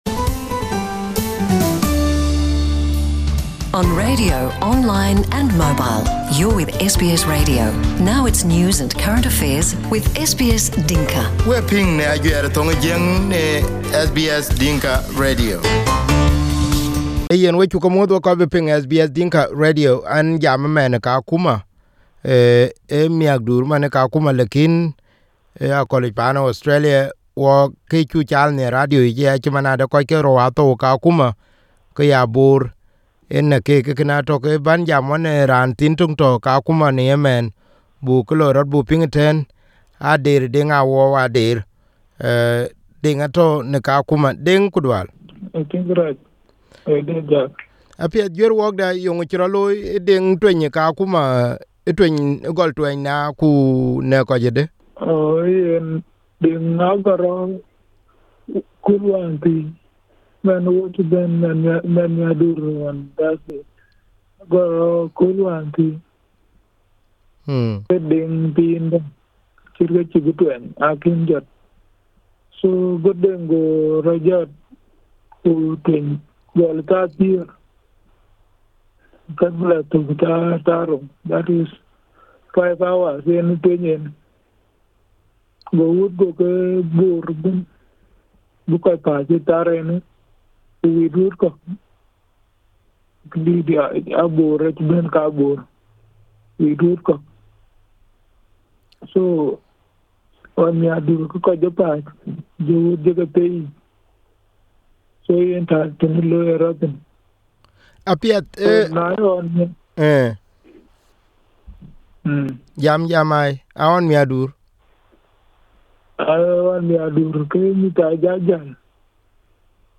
In an interview with SBS Dinka